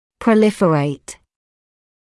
[prə’lɪfəreɪt][прэ’лифэрэйт]пролиферировать, разрастаться; быстро увеливаться в количестве
proliferate.mp3